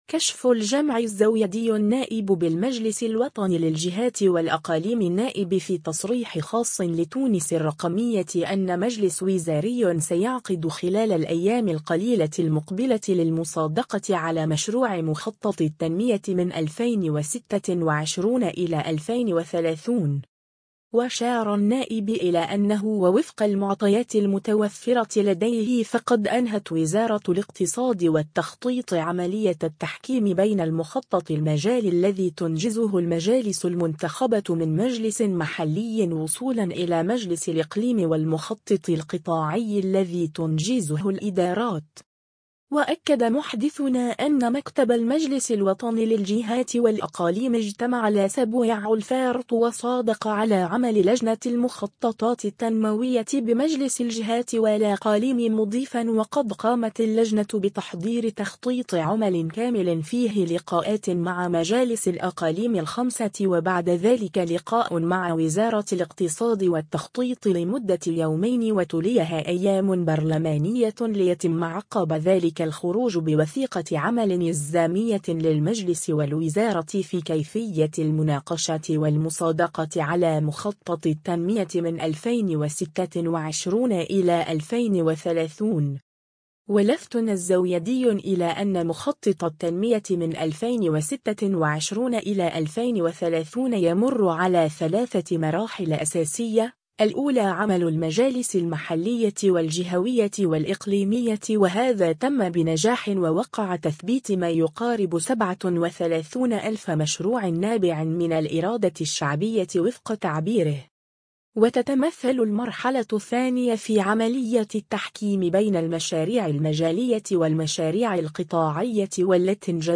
كشف الجمعي الزويدي النائب بالمجلس الوطني للجهات والأقاليم النائب في تصريح خاص لـ”تونس الرقمية” أن مجلس وزاري سيعقد خلال الأيام القليلة المقبلة للمصادقة على مشروع مخطط التنمية 2026-2030.